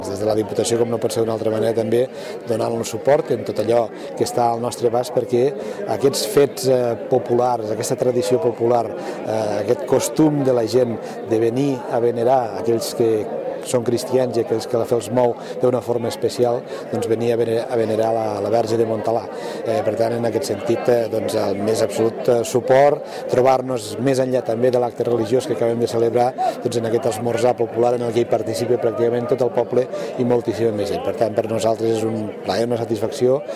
El president de la Diputació de Lleida, Joan Reñé, ha presidit aquest dimarts els actes de la Festa de la Mare de Déu de Montalbà, que se celebra a l’ermita de la Verge de Montalbà, a Preixana.
Reñe_Festa_Montalba_Preixana.mp3